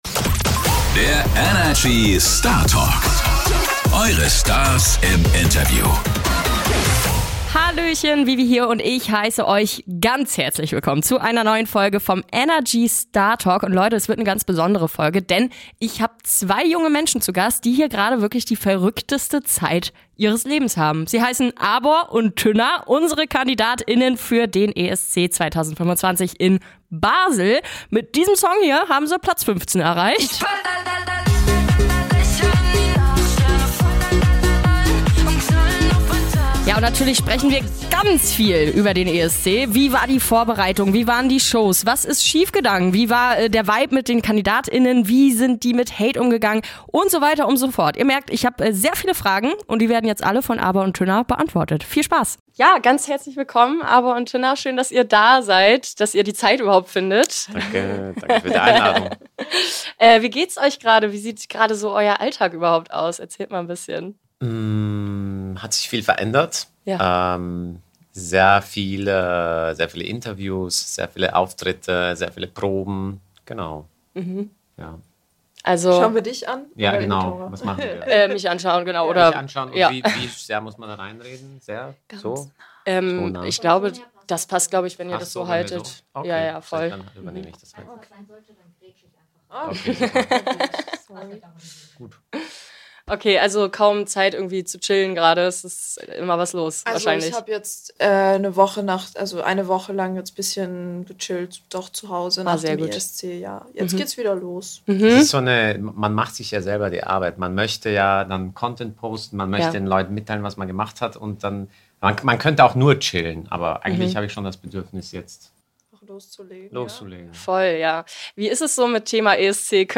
In dieser Folge sprechen wir mit Abor & Tynna, unseren Kandidat*innen für den Euro Vision Song Contest 2025 in Basel! Mit ihrem Song "Baller" haben sie für Deutschland Platz 15 geholt.